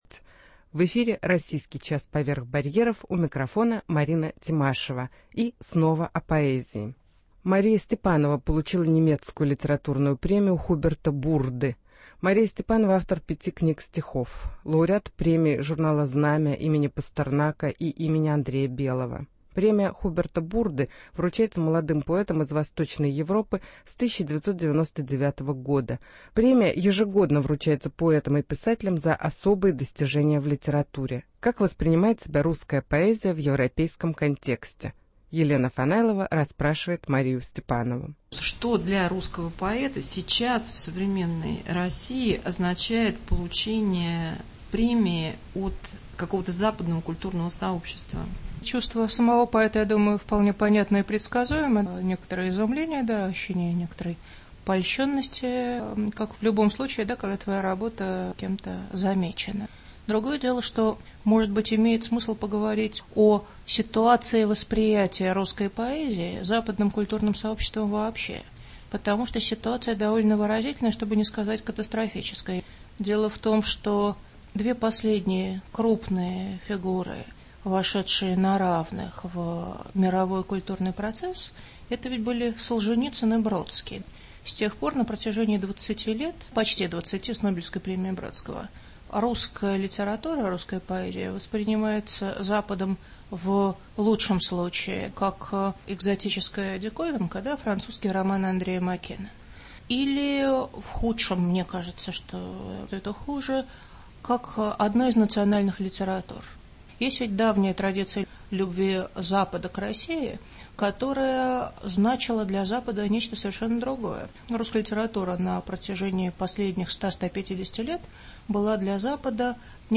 интервью с Марией Степановой, лауреатом премии Хуберта Бурды